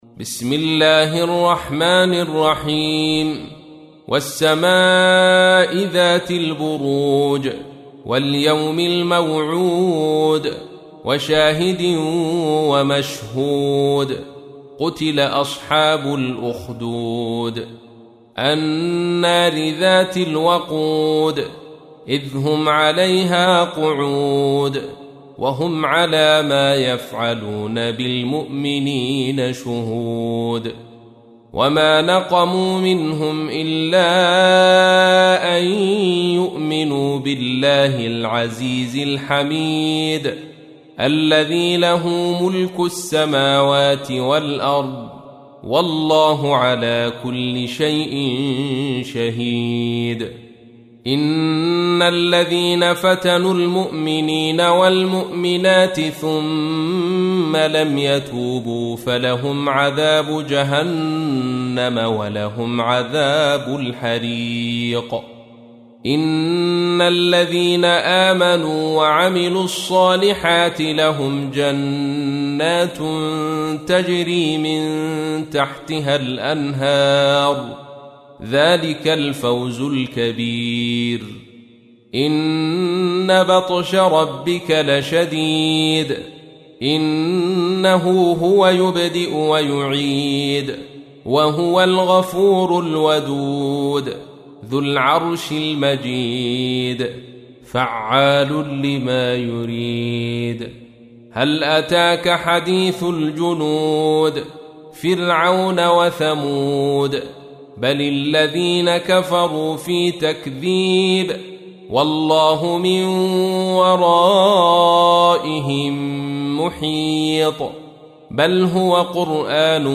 تحميل : 85. سورة البروج / القارئ عبد الرشيد صوفي / القرآن الكريم / موقع يا حسين